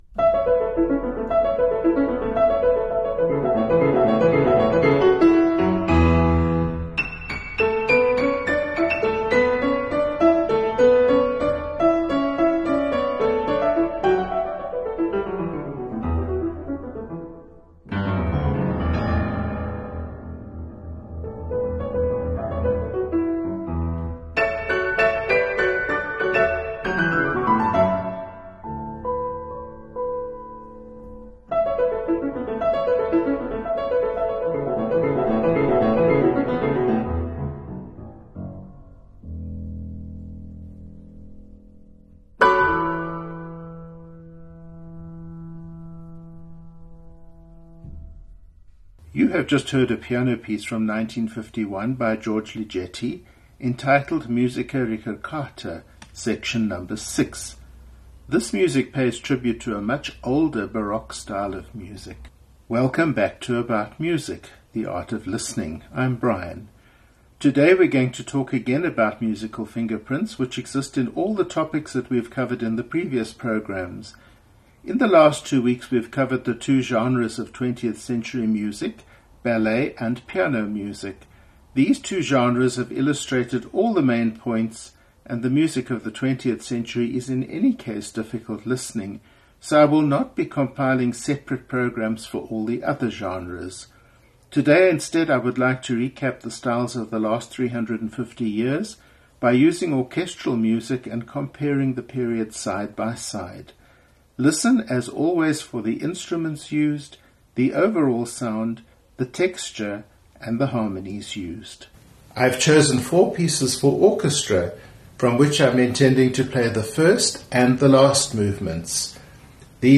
Today is about recognizing the sound of a Baroque orchestra, vs a Classical orchestra, vs a Romantic orchestra, vs a Modern (20th century) orchestra. Even if this is something you already know, join me anyway for music by Bach, Haydn, Schumann, and Bartok.